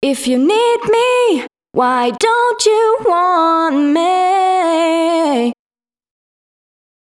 013 female.wav